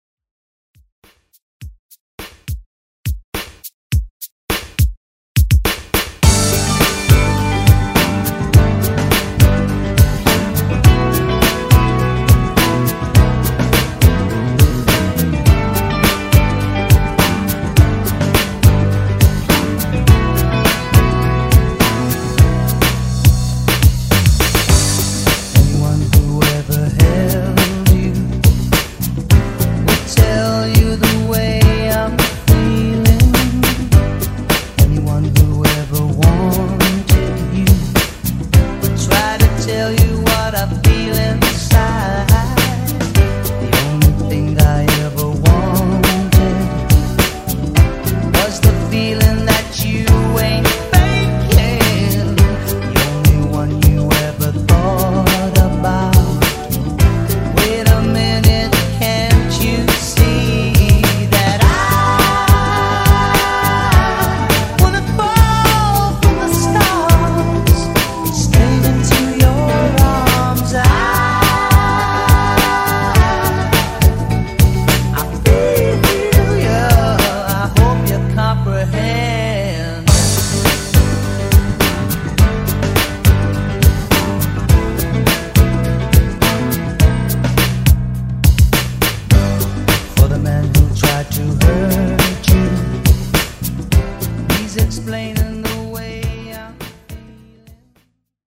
Genres: 90's , RE-DRUM
Clean BPM: 104 Time